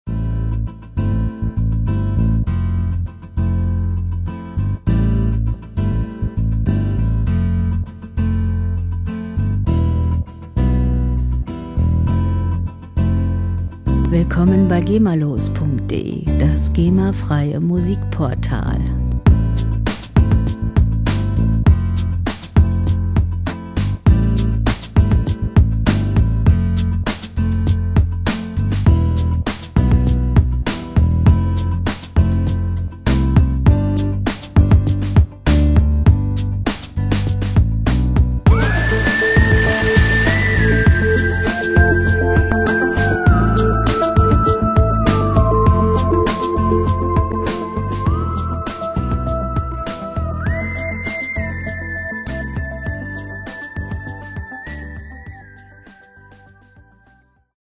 World Pop Music aus der Rubrik "Weltenbummler"
Musikstil: Chillout Pop
Tempo: 100 bpm
Tonart: A-Moll
Charakter: verträumt, freundlich